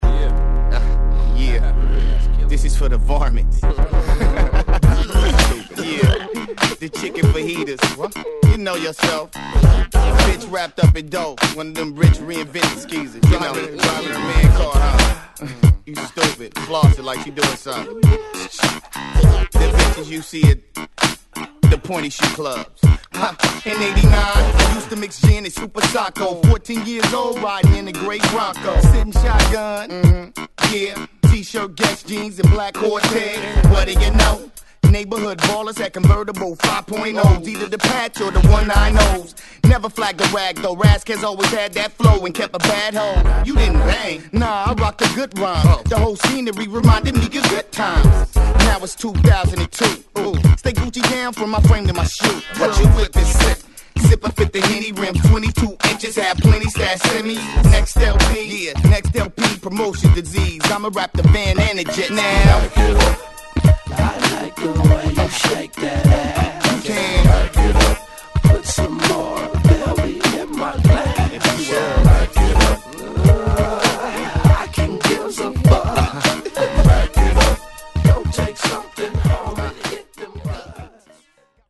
ホーム HIP HOP UNDERGROUND 12' & LP R